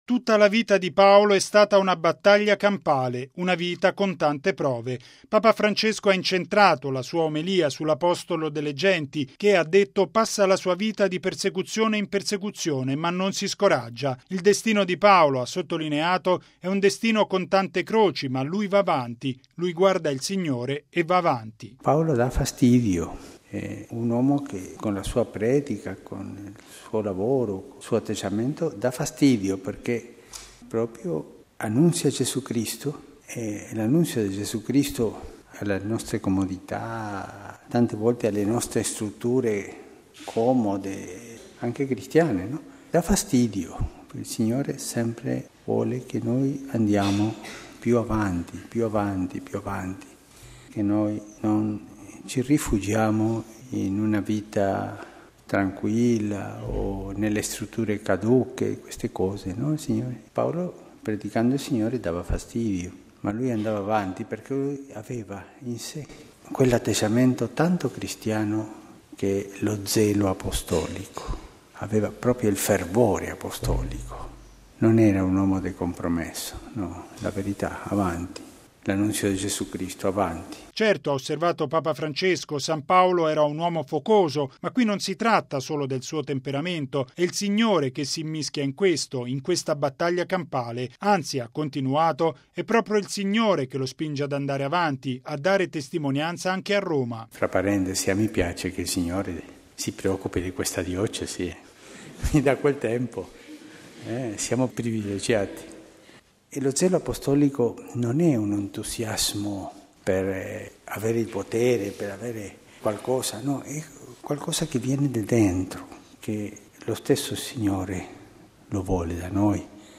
◊   La Chiesa ha tanto bisogno del fervore apostolico che ci spinge avanti nell’annuncio di Gesù. E’ quanto sottolineato, stamani, da Papa Francesco nella Messa alla Casa Santa Marta.